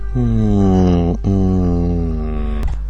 Epic noise - Hmmm mmm
Category: Sound FX   Right: Personal